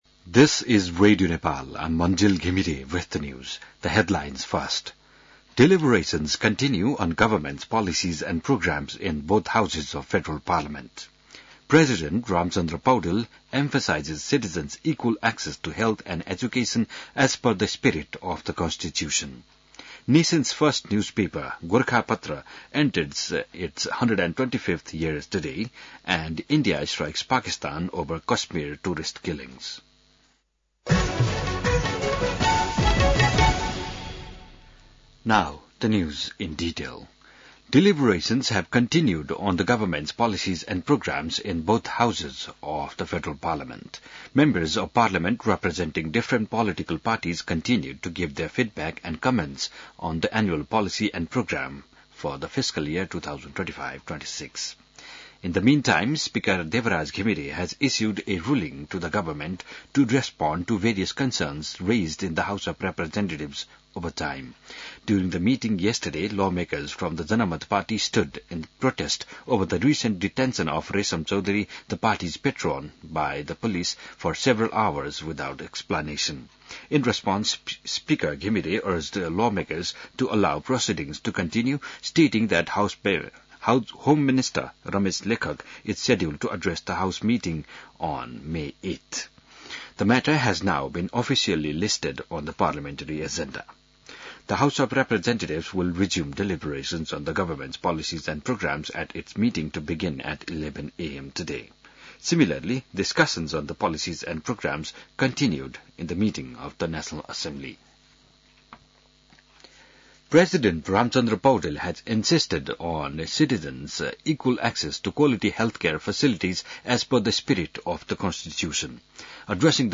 बिहान ८ बजेको अङ्ग्रेजी समाचार : २४ वैशाख , २०८२